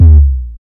Waka KICK Edited (76).wav